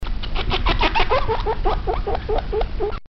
Крякающие звуки